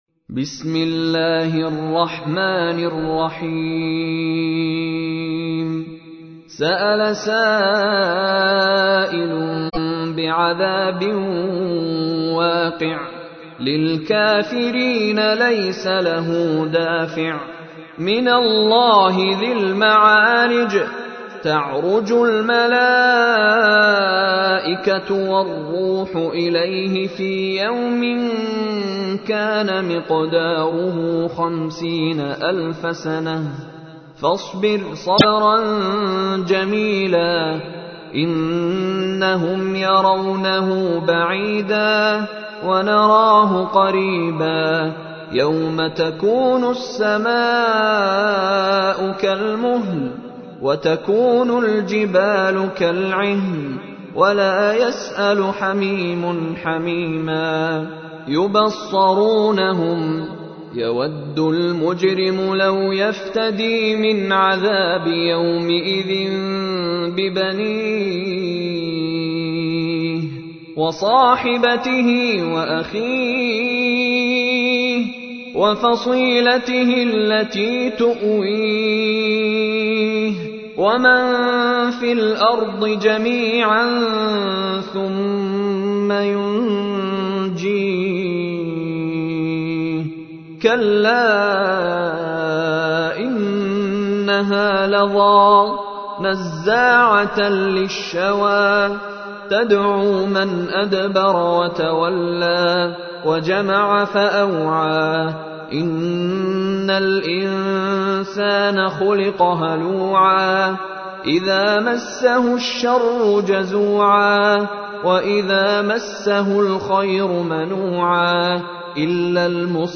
تحميل : 70. سورة المعارج / القارئ مشاري راشد العفاسي / القرآن الكريم / موقع يا حسين